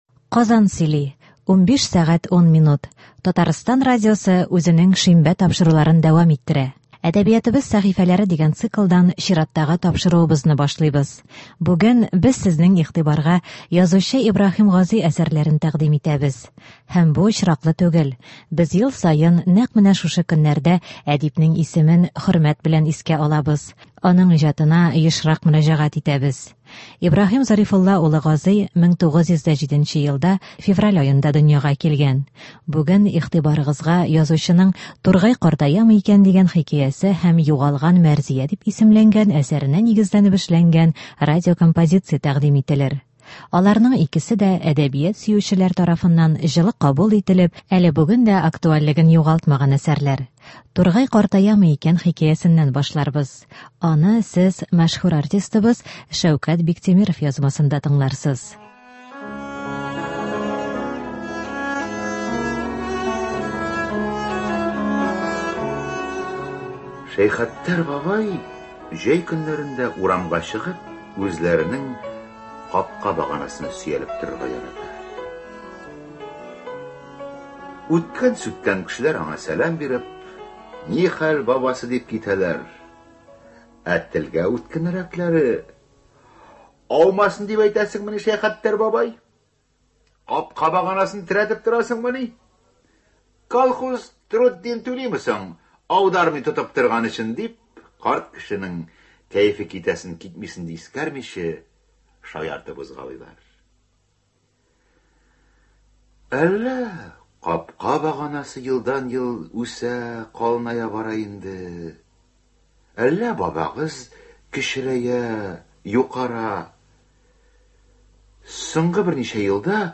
Бүген игътибарыгызга язучының “Тургай картаямы икән?” дигән хикәясе һәм “Югалган Мәрзия” дип исемләнгән әсәренә нигезләнеп эшләнгән радиокомпозиция тәкъдим ителер.